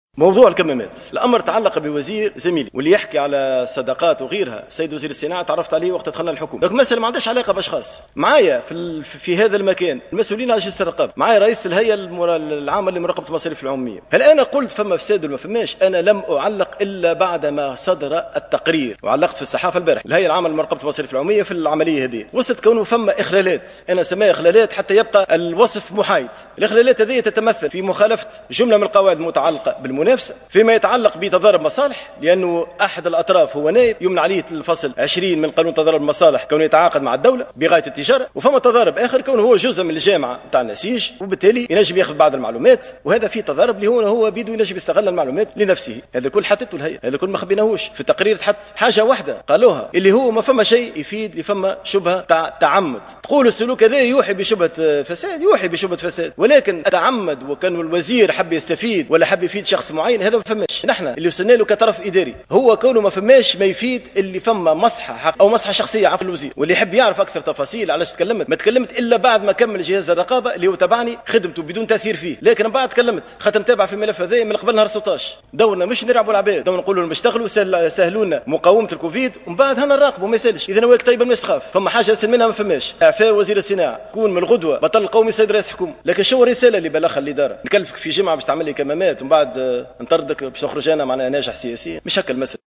و أضاف عبّو في إجابته على تساؤلات النواب اليوم في جلسة عامة للحوار مع أعضاء الحكومة، أن صفقة الكمامات بالنسبة للطرف الثاني، صاحب المصنع و النائب بالبرلمان، تُصنف ضمن تضارب المصالح، حسب تعبيره.